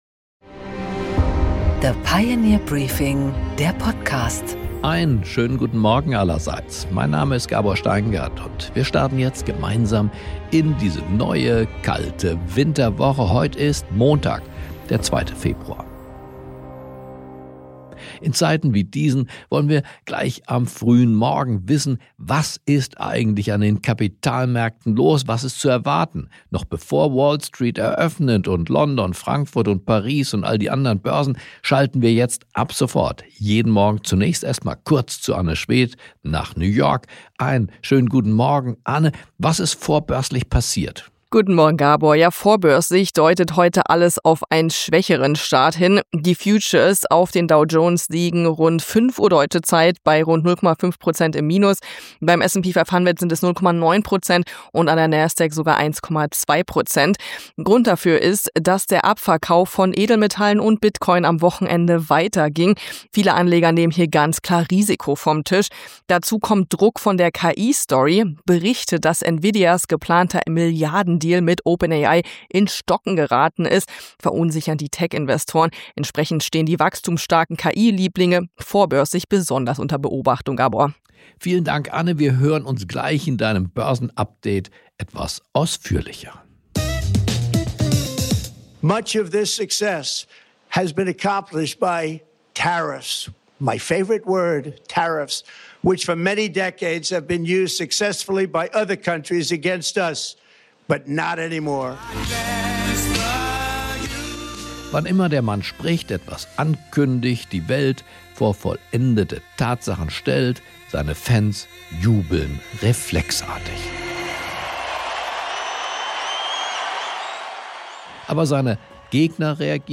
Gabor Steingart präsentiert das Pioneer Briefing.
Im Gespräch: Albrecht Broemme, Ex-Präsident des THW und Experte für Katastrophenschutz, erklärt im Gespräch mit Gabor Steingart den Brandanschlag auf das Berliner Stromnetz, die offengelegten Schwächen und die Notwendigkeit, kritische Infrastruktur robuster, redundanter und weniger leicht angreifbar zu machen.